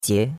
jie2.mp3